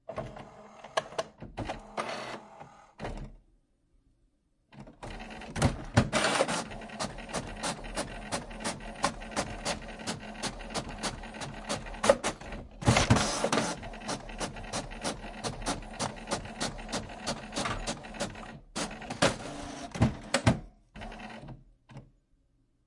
技术 " DN KP 打印机 02
描述：办公室打印机的环境录音使用MP3设备
标签： 环境 效果 打印机
声道立体声